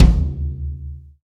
taiko-normal-hitfinish.ogg